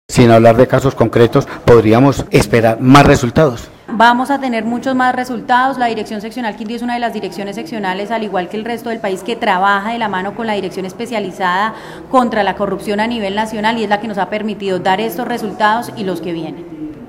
El Portal Web Periodismo Investigativo dialogó con la directora seccional de fiscalías del Quindío Dra. Leonor Merchán Lopera, quien explicó que ‘’el trabajo de la mano con la dirección especializada contra la corrupción ha permitido dar estos resultados y los que vienen’’